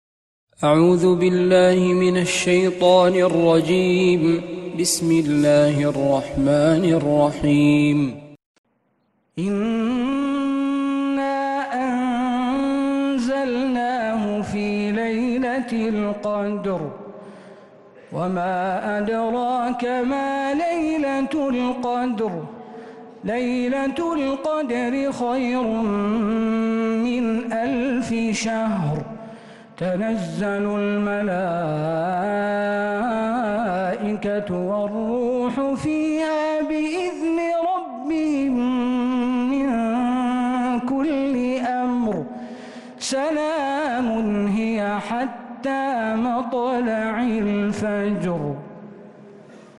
سورة القدر من تراويح الحرم النبوي